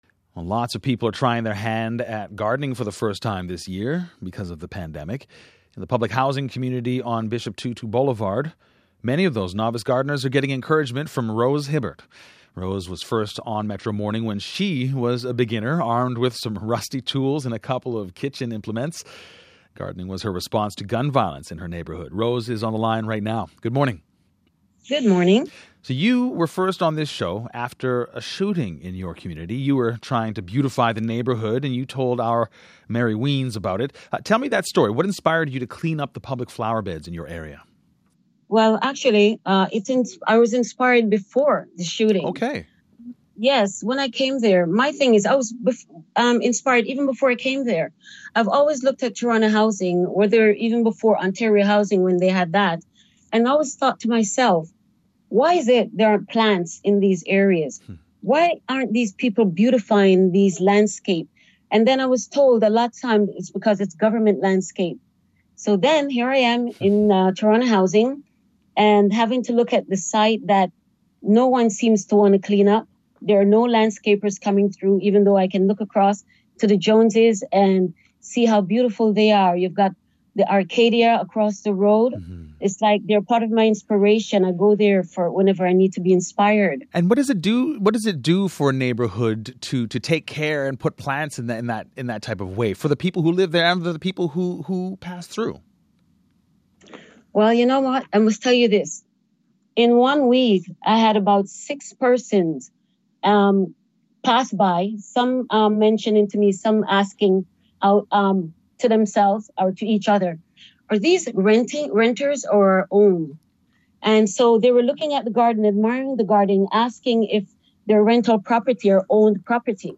Listen to the CBC radio interview